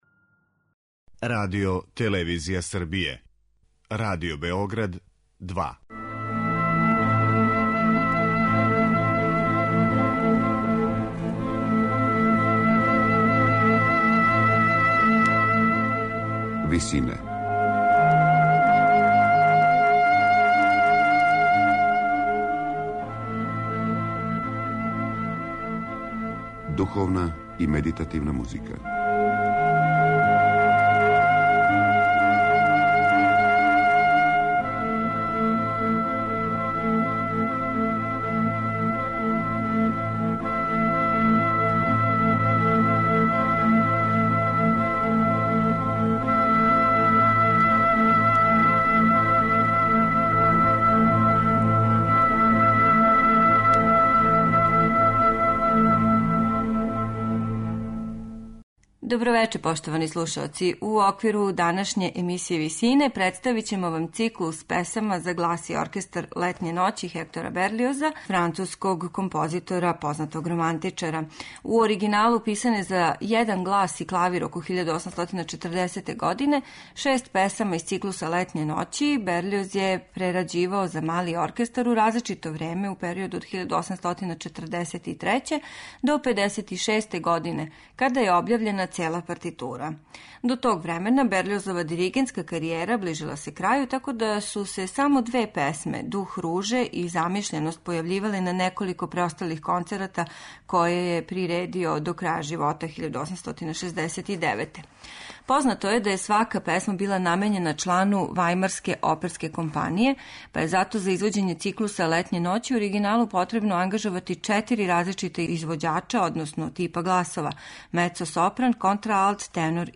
Француски композитор Хектор Берилоз је свој циклус песама за глас и оркестар - Летње ноћи писао у периоду од 1843. до 1856. године, када је објављена цела партитура.